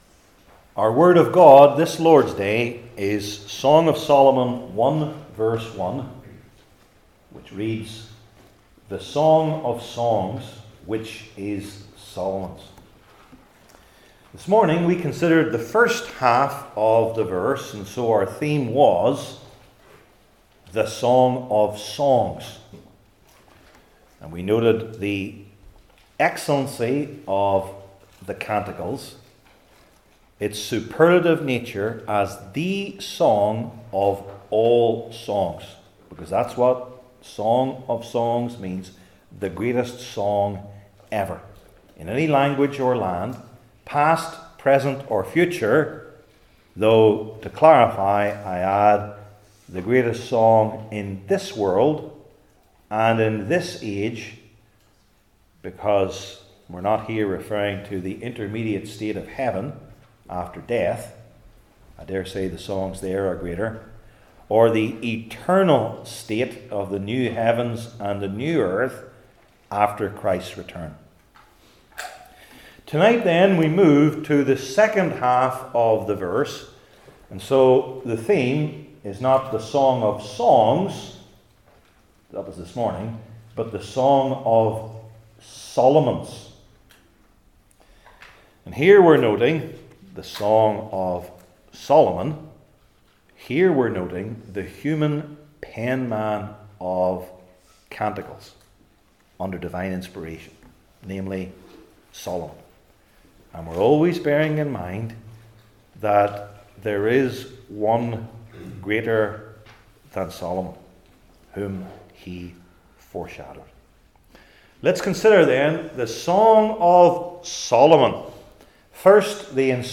Song of Solomon 1:1 Service Type: Old Testament Sermon Series I. The Inspired Penman II.